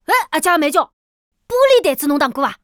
序章与第一章配音资产
c02_5小男孩_1.wav